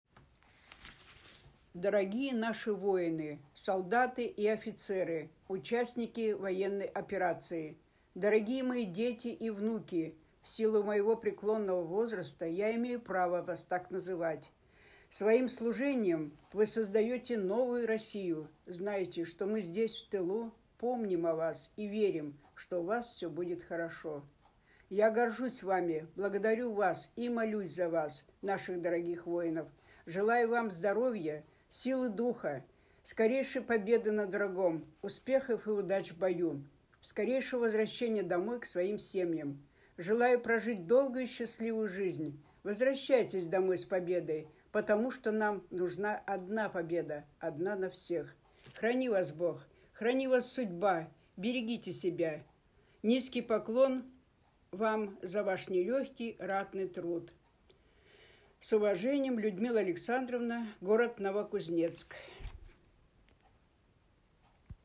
Незрячие и слабовидящие читатели пишут «звуковые» письма в поддержку героев СВО.